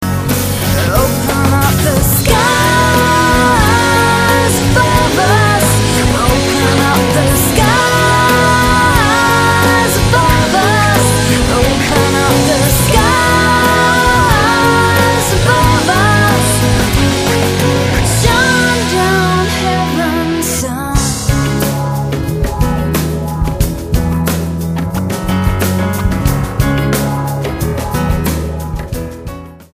STYLE: Pop
skillfully weaves engaging pop and rock rhythms